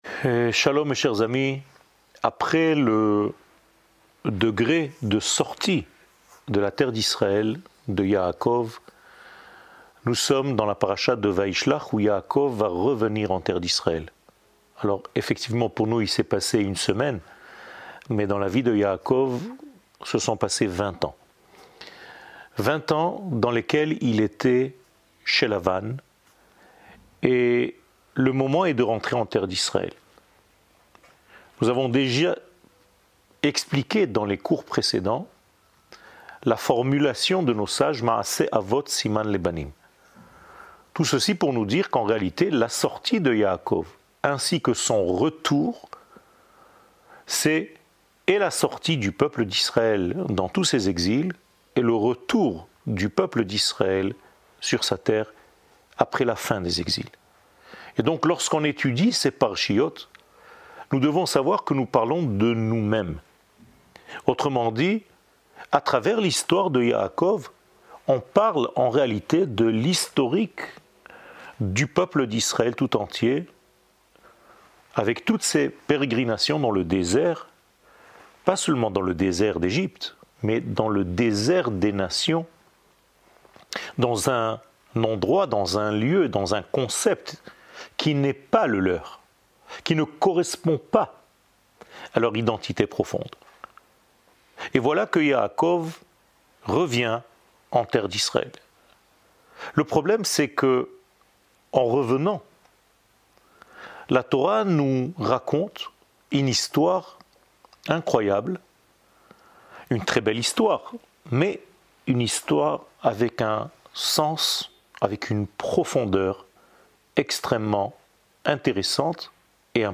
שיעור מ 08 דצמבר 2022 10MIN הורדה בקובץ אודיו MP3 (9.86 Mo) הורדה בקובץ וידאו MP4 (28.82 Mo) TAGS : שיעורים קצרים